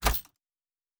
Fantasy Interface Sounds
Locker 1.wav